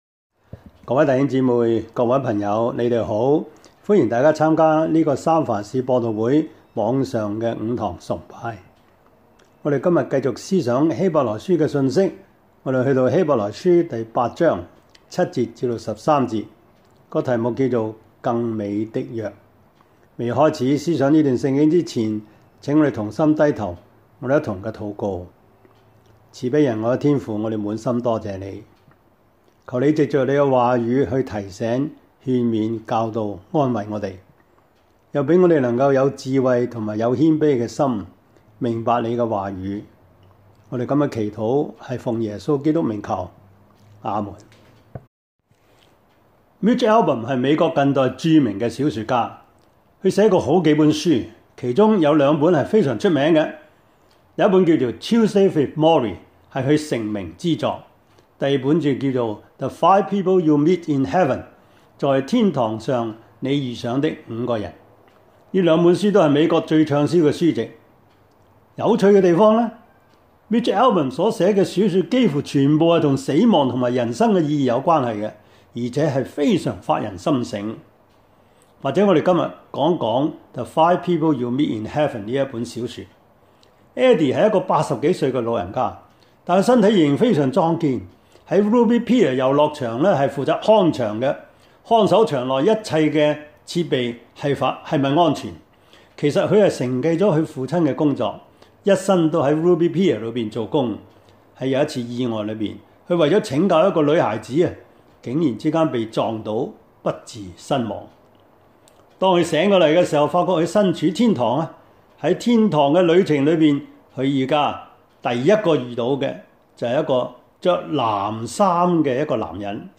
Service Type: 主日崇拜
Topics: 主日證道 « 是誰在耽延?